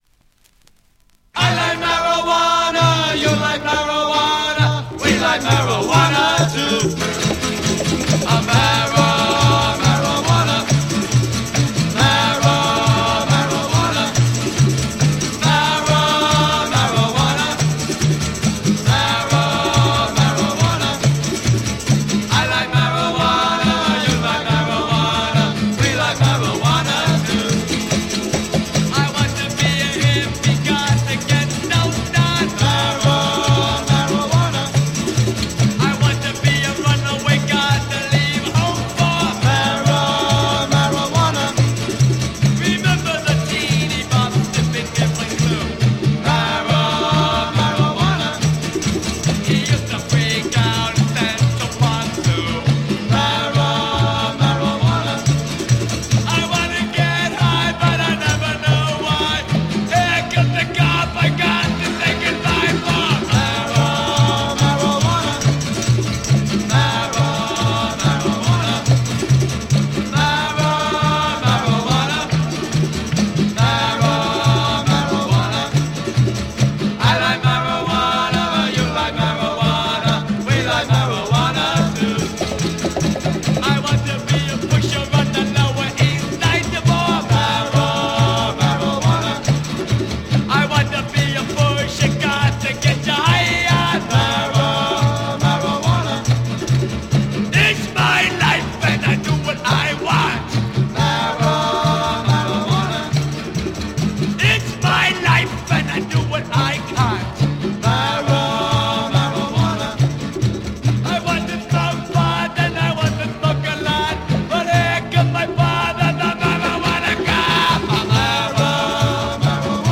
Hippy blues soul psych rock
nice hippy sound